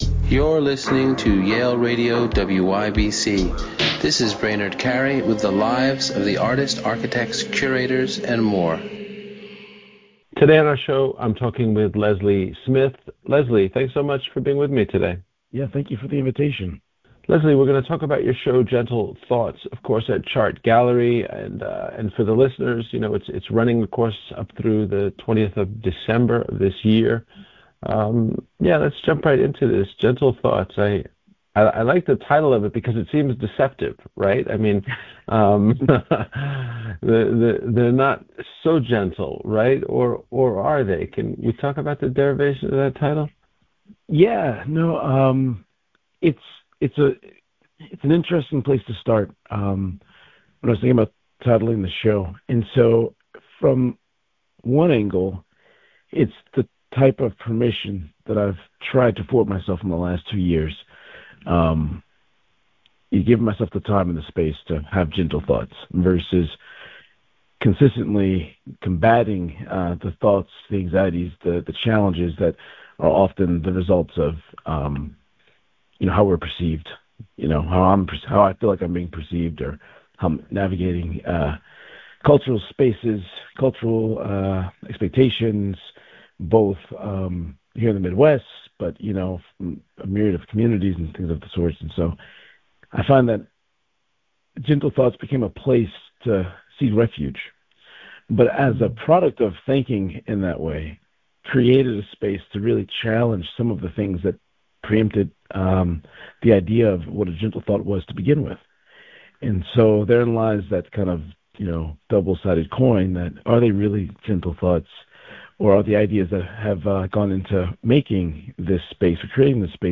Interviews from Yale University Radio WYBCX